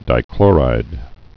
(dī-klôrīd)